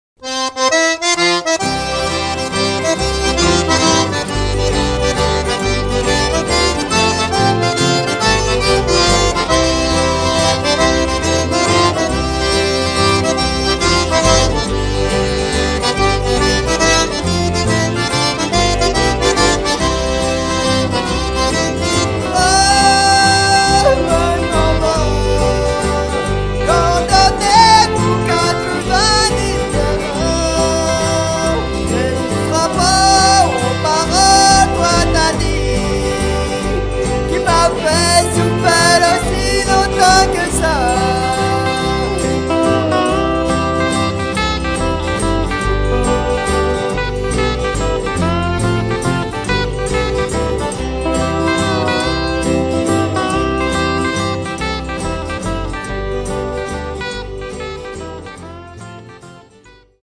CD 1 (Cajun)